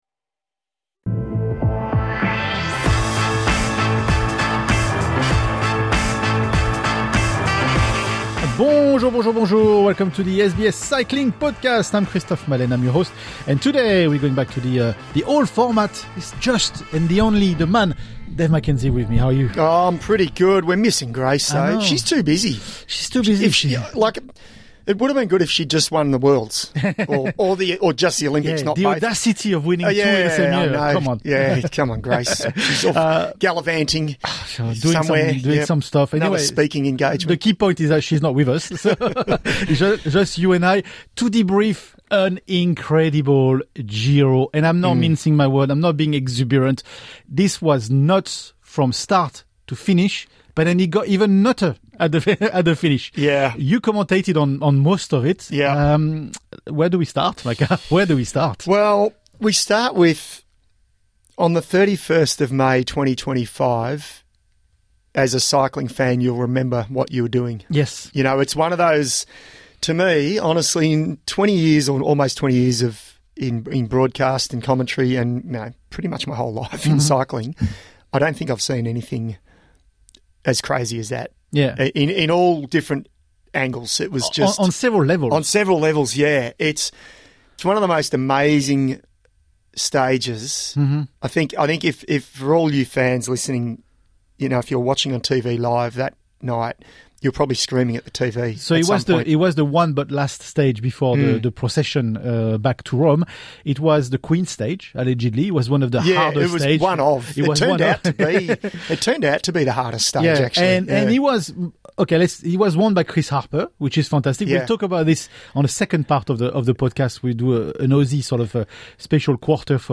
Tactics take centre stage as the duo analyses team strategies, especially those of UAE Team Emirates, and how key decisions could have shifted the race outcome. The conversation also explores the role of race radios and evolving team dynamics in modern cycling.